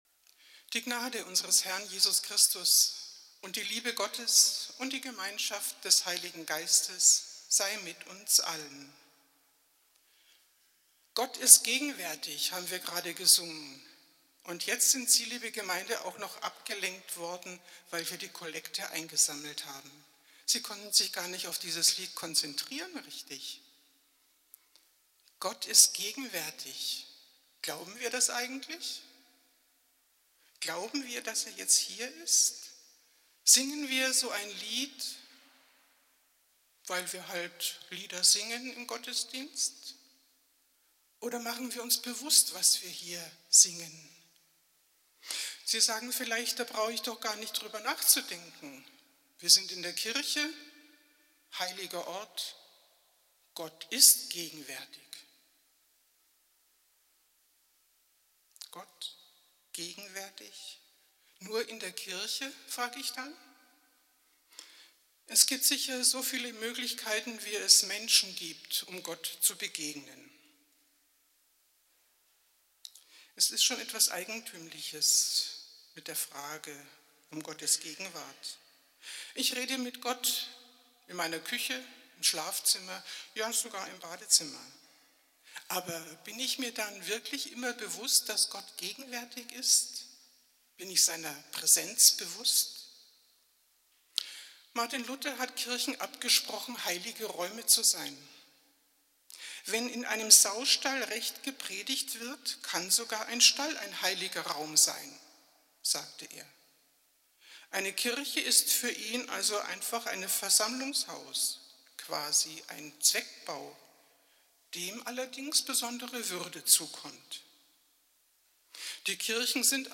Predigt vom Sonntag, 26.08.2018